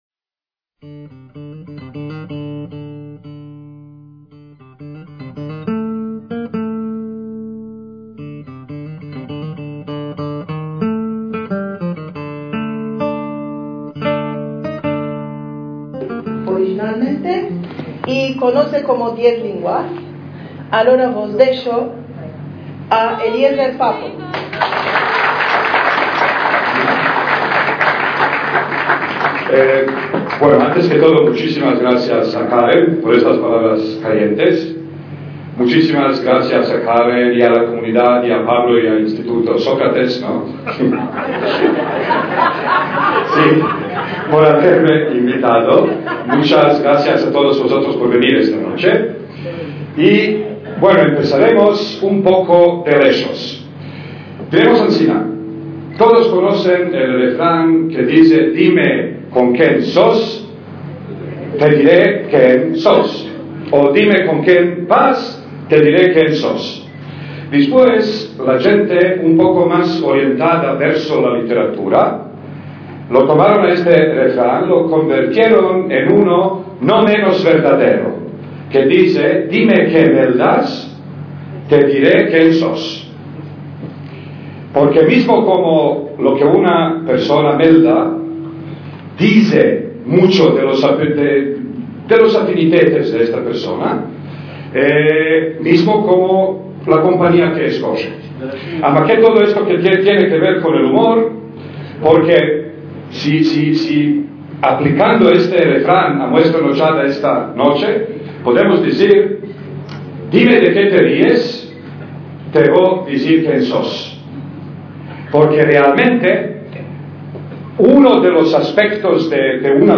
ACTOS EN DIRECTO - El 26 de noviembre de 2014 tuvo lugar una sesión enteramente dedicada al característico humor judío sefardí organizada por el Instituto Cervantes de Estambul y el Centro de Investigaciones sobre la Cultura Sefardí Otomano Turca.